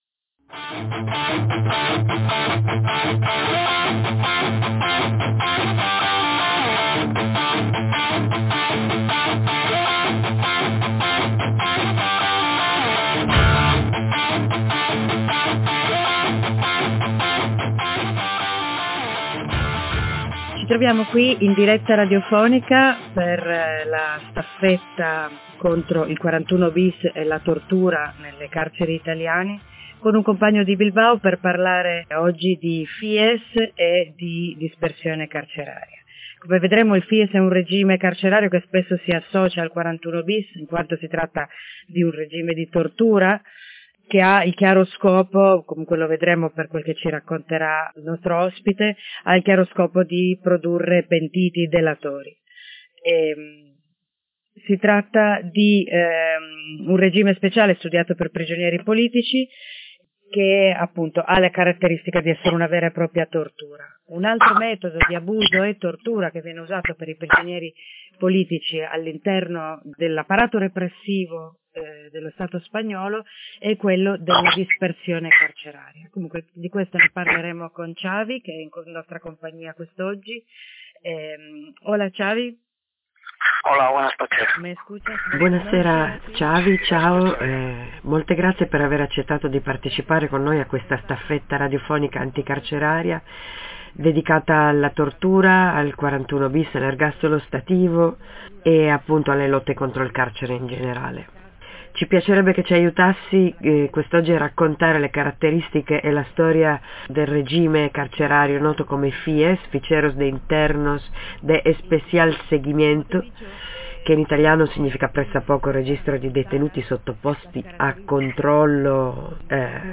A distanza di più di un anno dalla staffetta radiofonica carceraria del 22 aprile 2023 in solidarietà con Alfredo Cospito in sciopero della fame, pubblichiamo il contributo di Radio Bizarre a quella lunga giornata di dirette a cui parteciparono tante diverse radio di movimento di tutta italia.
Intervista con un compagno basco sul regime carcerario speciale fies (fichero de internos de especial seguimento) e dispersione carceraria per la staffetta anticarceraria:
intervista-su-fies-e-dispersione-carceraria-def.mp3